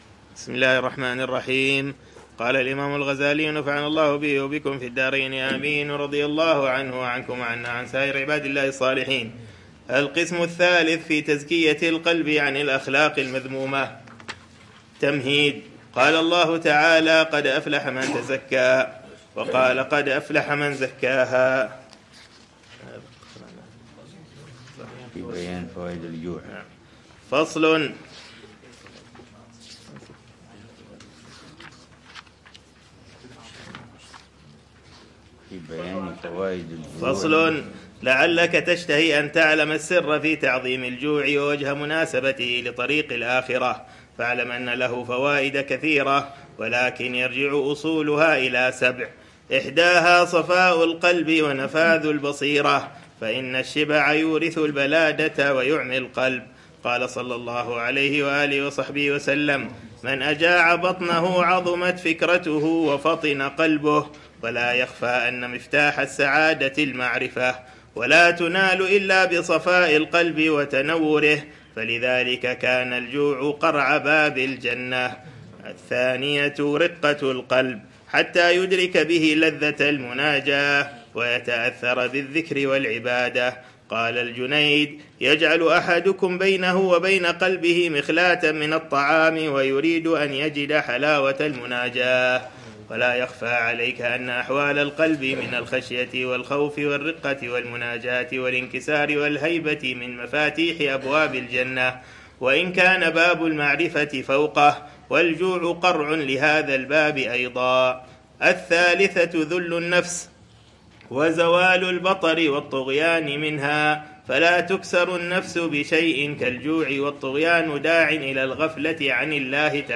درسٌ يجمع تهذيب الجوف واللسان بخطواتٍ واضحة لتزكيةٍ أرسخ